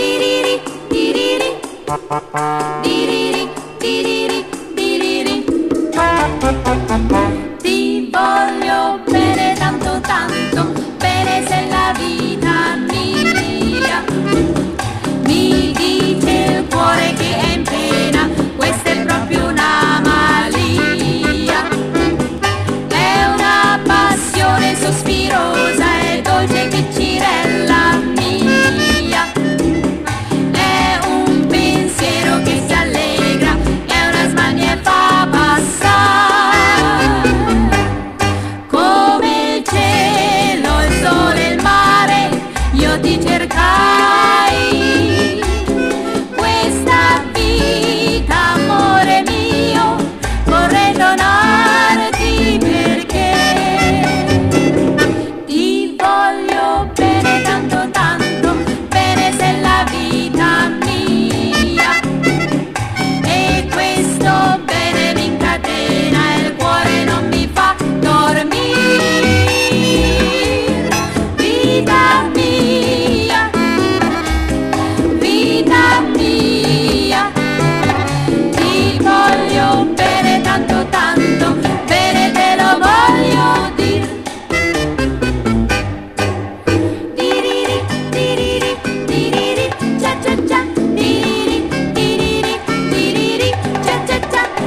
INDIE POP
ALTERNATIVE (UK)
淡いシューゲイズ・サウンドで胸を焦がす轟音ギターポップ/パワーポップ路線の